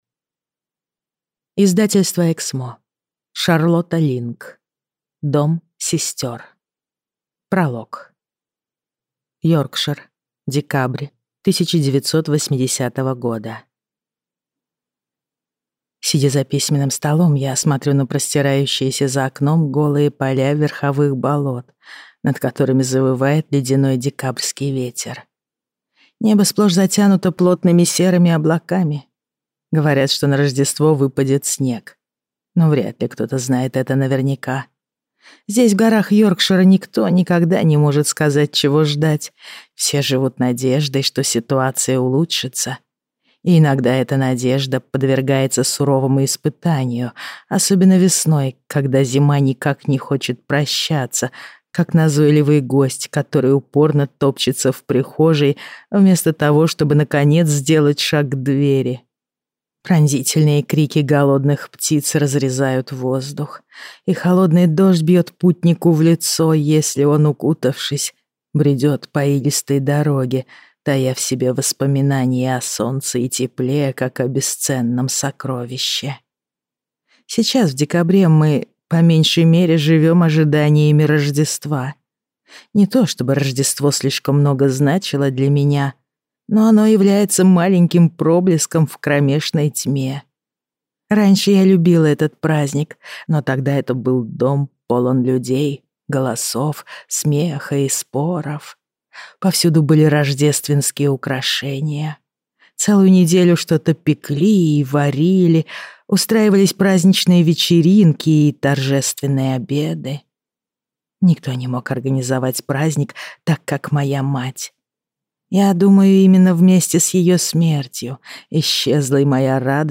Аудиокнига Дом сестер | Библиотека аудиокниг